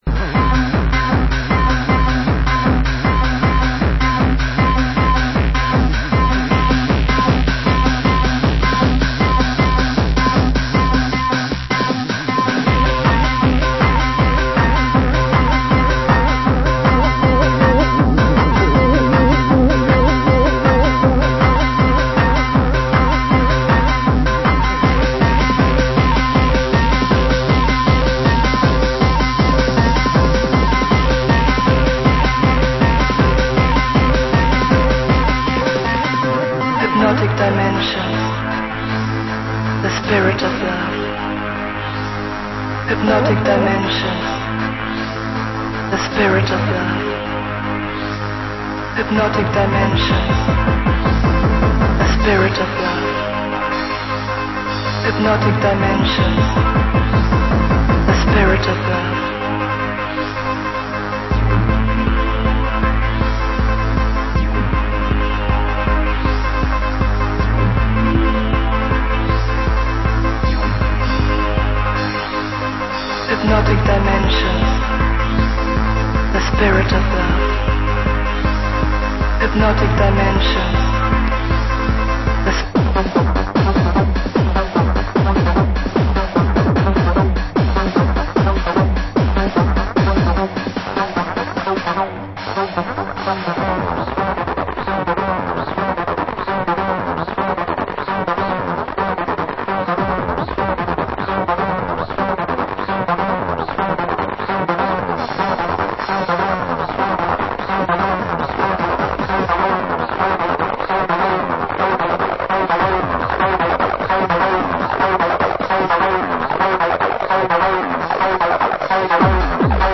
Genre: Techno